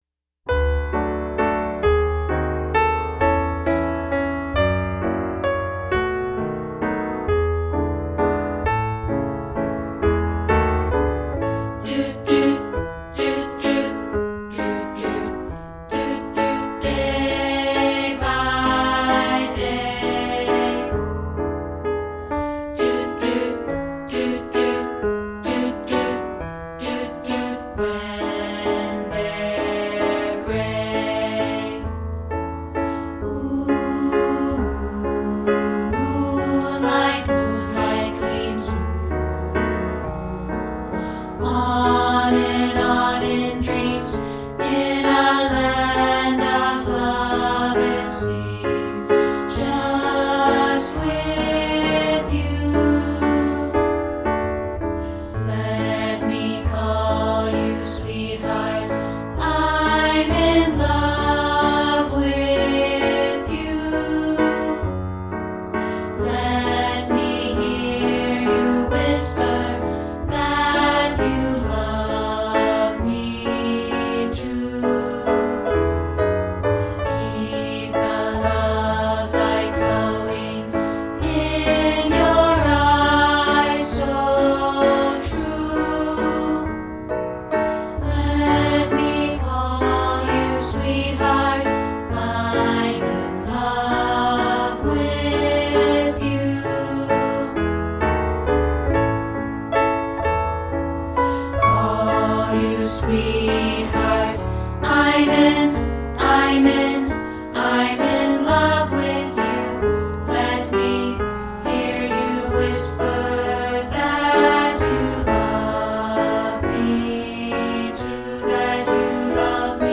including this rehearsal track for part 3.